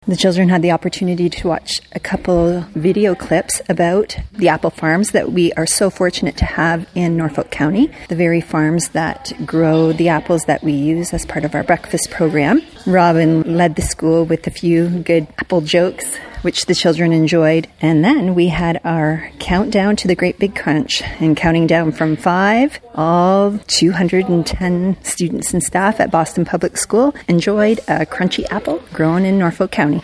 All of the students gathered in the gymnasium for an assembly to crunch together.